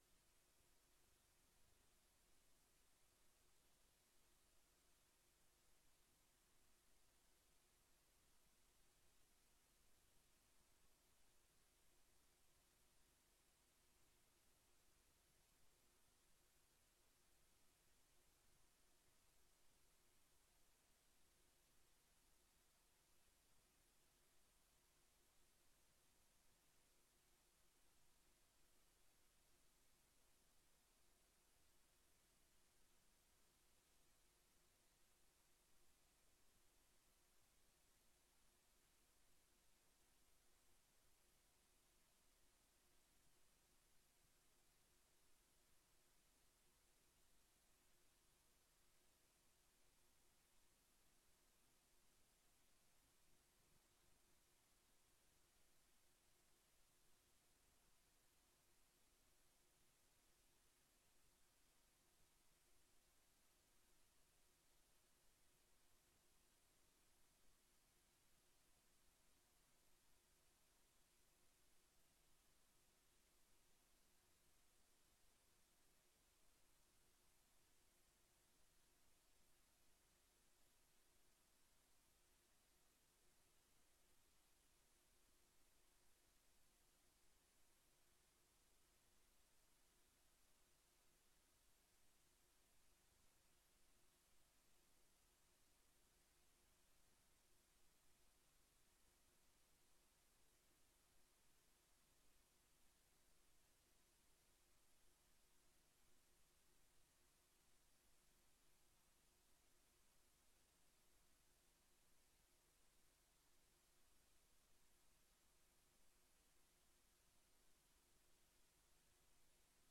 Locatie: Raadzaal
Oordeelsvormende vergadering
Deze keer vindt de bespreking plaats als oordeelsvormende vergadering , waarin raadsleden hun standpunten delen en mogelijke aanpassingen verkennen.